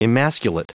Prononciation du mot emasculate en anglais (fichier audio)
Prononciation du mot : emasculate